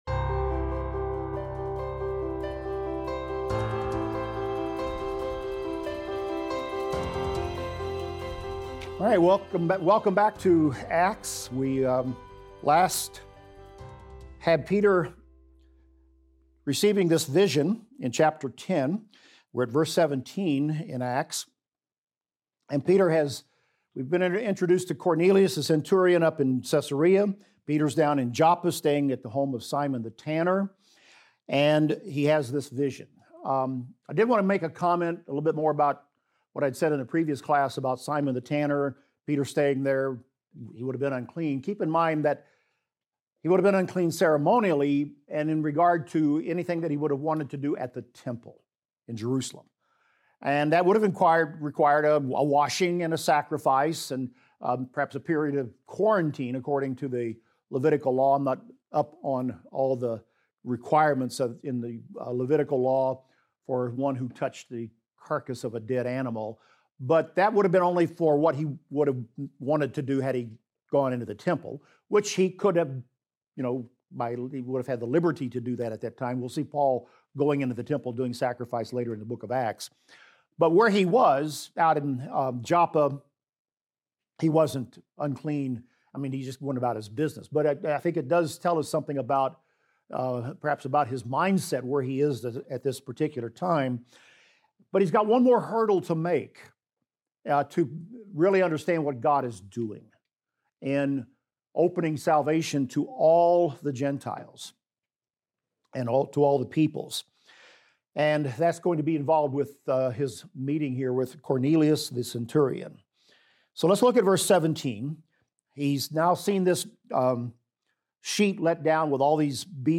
In this class, we will discuss Acts 10:17 thru Acts 11:18 and continue the discussion of Peter's vision and his interaction with Cornelius. From this interaction, we will see Gentiles being baptized and receiving the Holy Spirit.